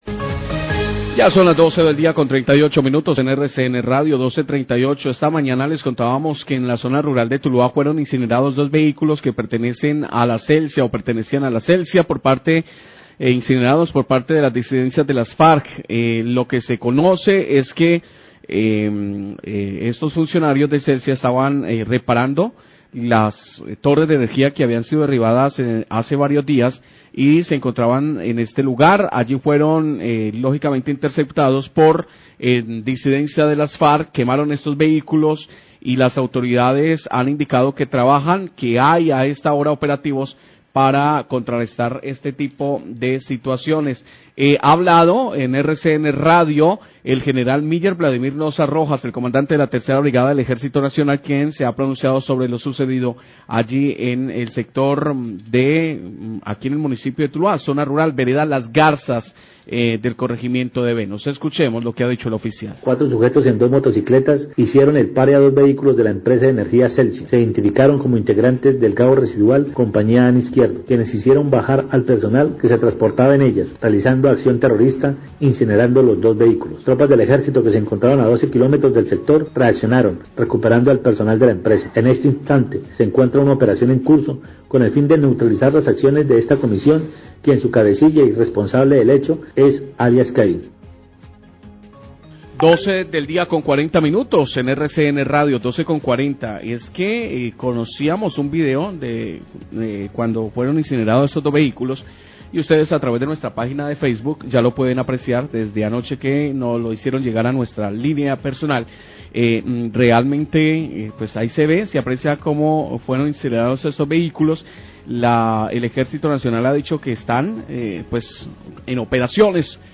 Cdte 3ra Brigada Ejército habla de enfrentamientos con disidencias de FARC tras quema de dos vehículos de Celsia
Radio